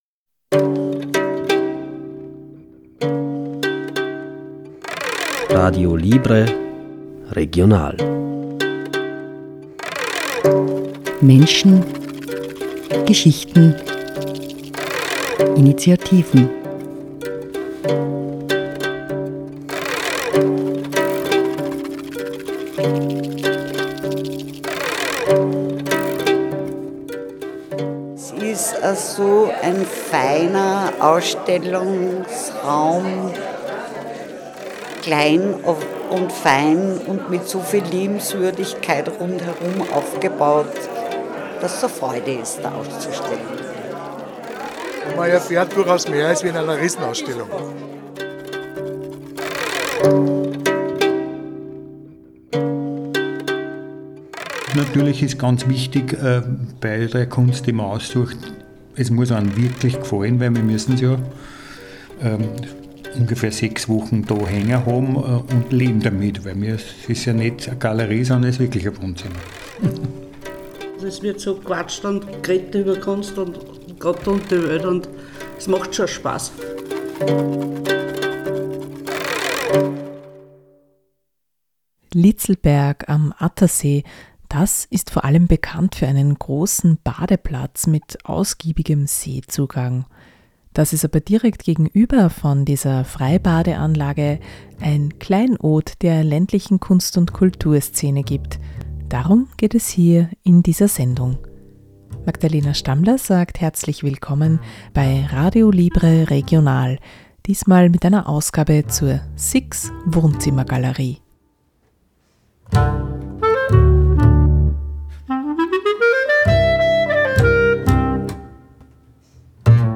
Diese Gelegenheit haben wir genützt, um der Wohnzimmergalerie einen Besuch abzustatten und herauszufinden: Was ist das denn überhaupt, eine Wohnzimmergalerie?